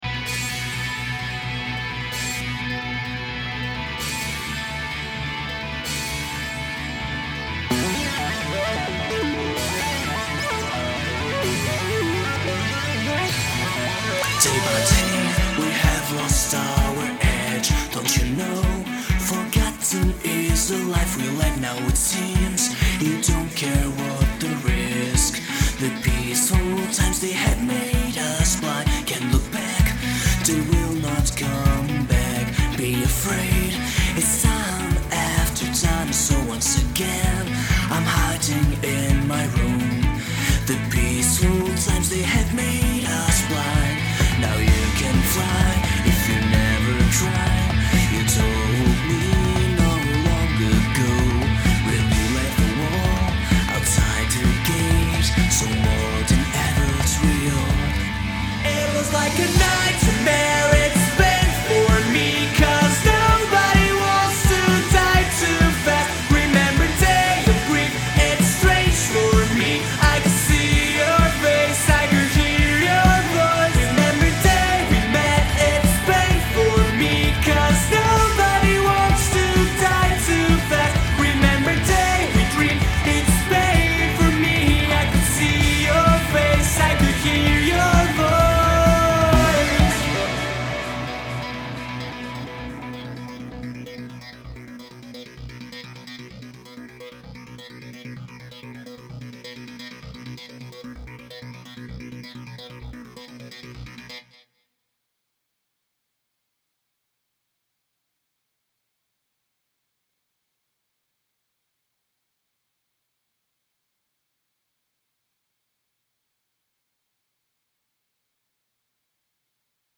My verse part is much louder than my chorus part, like in the chorus the voice just seems to go lower in volume and power, and i can't even touch anything or else it will peak on the red automatically.